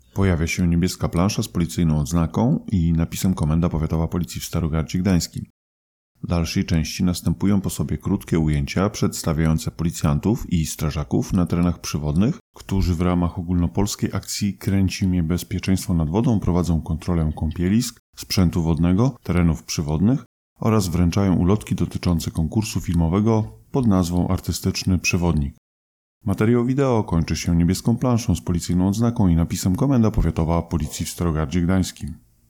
Nagranie audio Audiodeskrypcja do materiału wideo